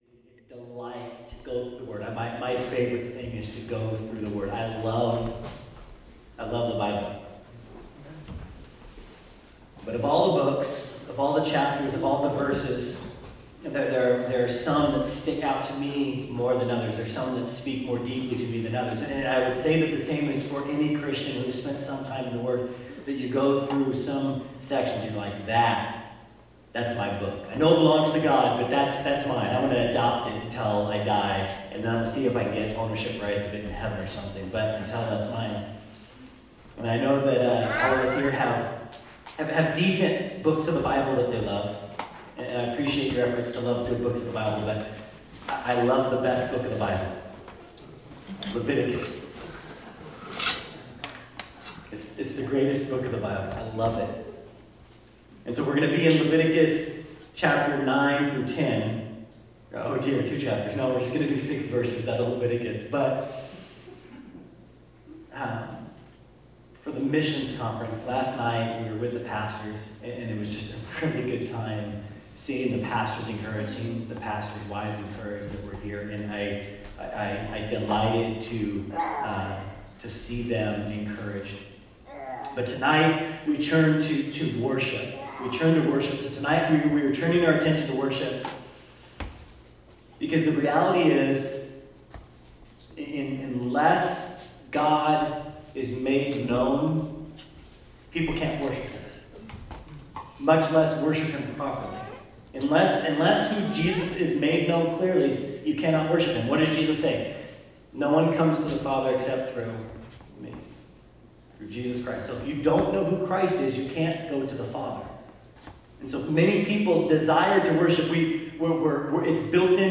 2019 Missions Conference: True Worship